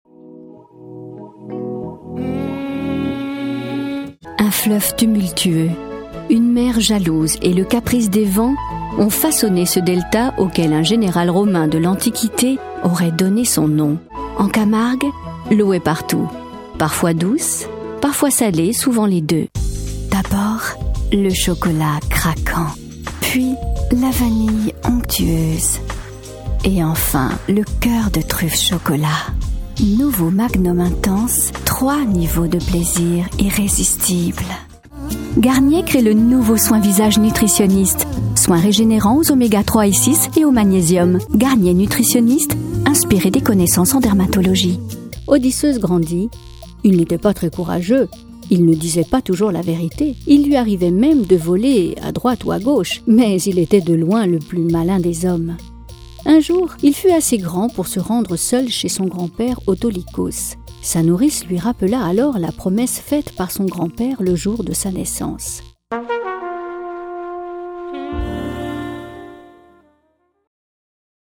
Voix jeune et claire, timbre médium aigu
J’enregistre également dans mon home studio pour répondre à vos attentes rapidement.
Voix off
Démo français